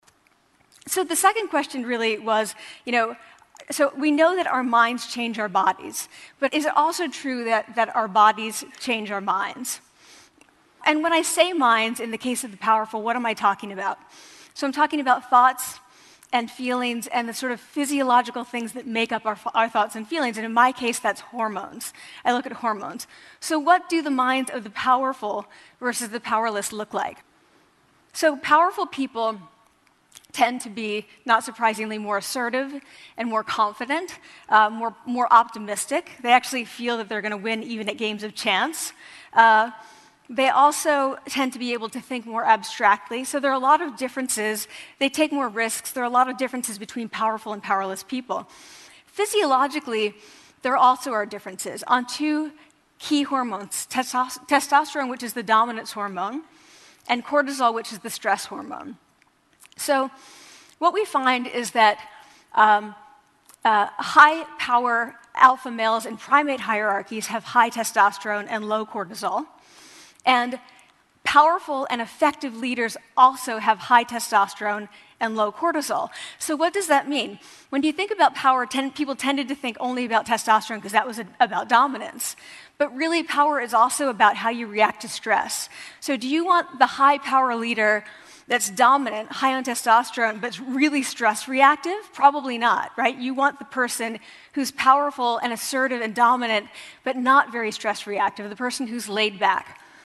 TED演讲：肢体语言塑造你自己(6) 听力文件下载—在线英语听力室